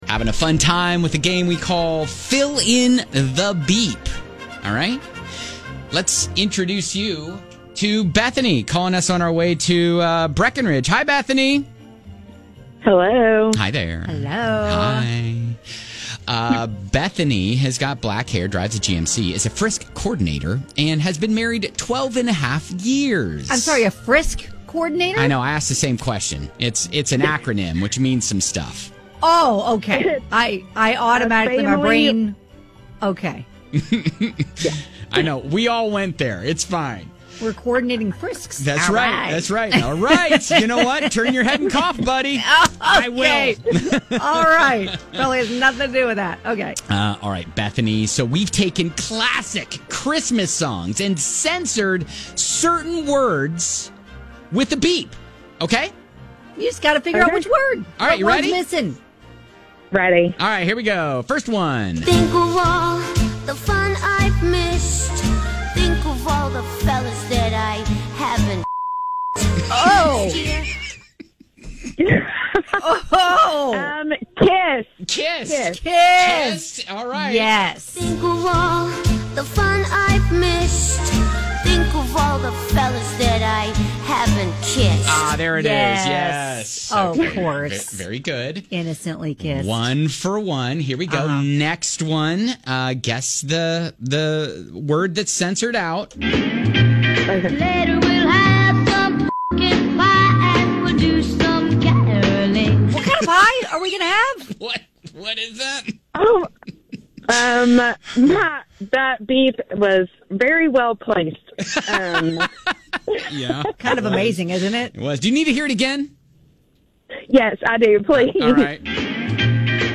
We’ve taken classic Christmas songs and censored certain words with a beep - just fill in the BEEP and win!